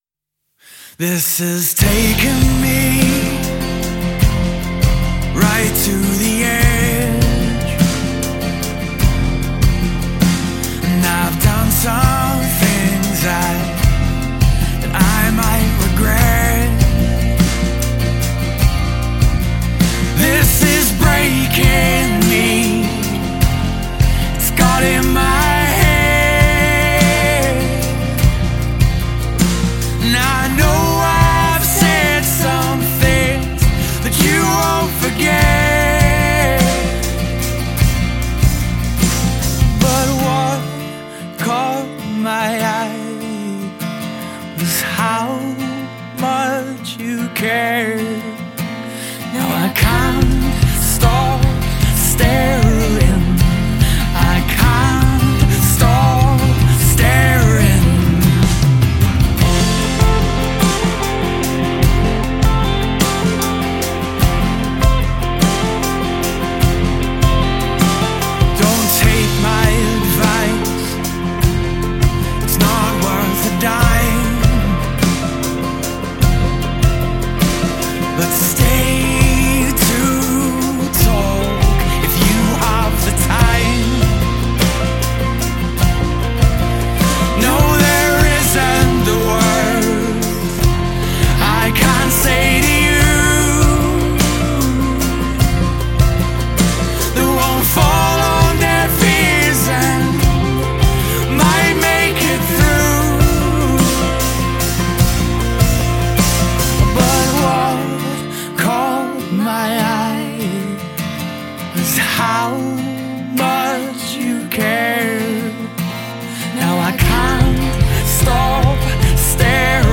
Folk-pop